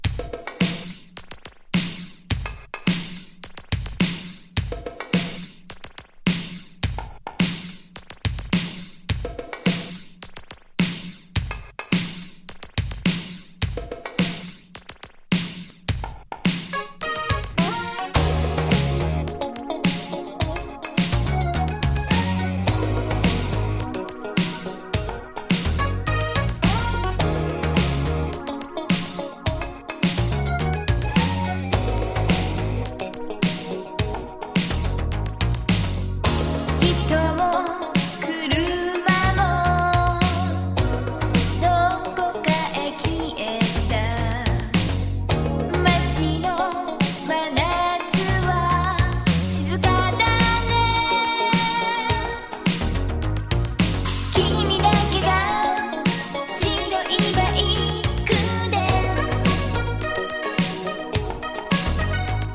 モダンソウル歌謡